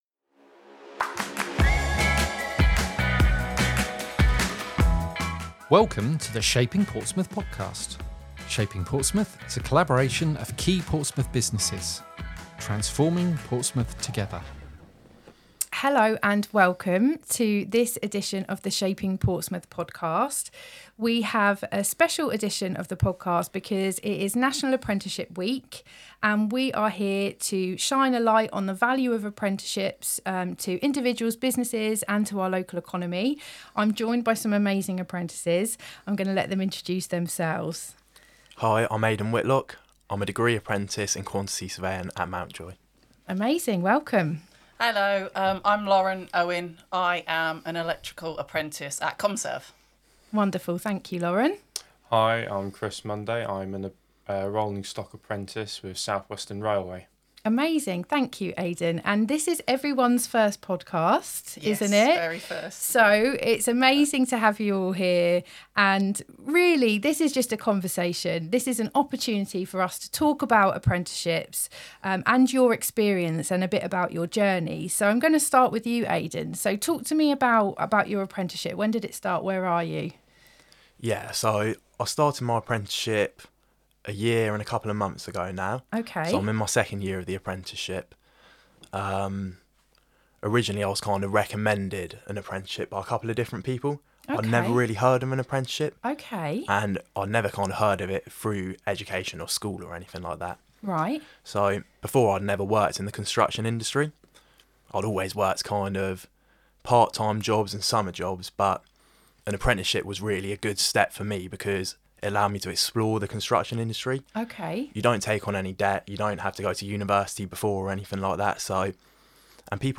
chats with apprentices